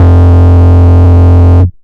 Menacing Bass 2 (JW3).wav